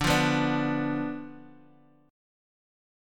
Listen to Dm6 strummed